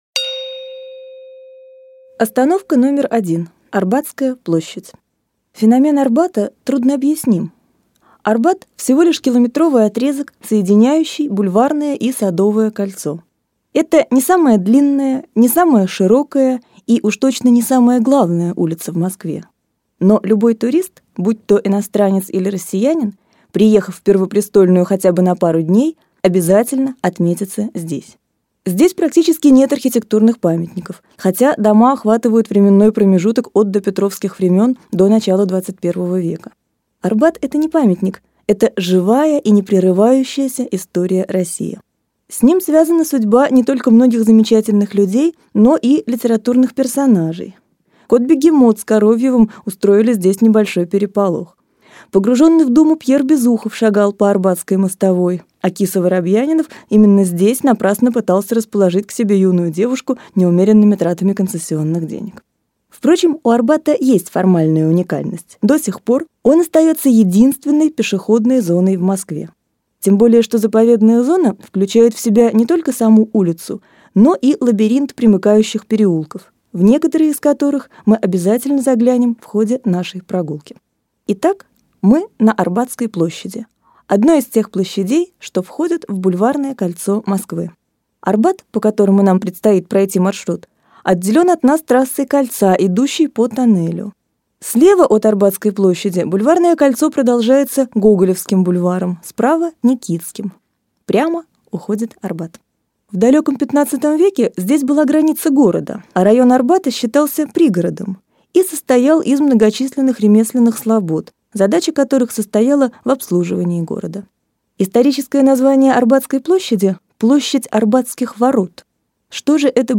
Аудиокнига Арбат | Библиотека аудиокниг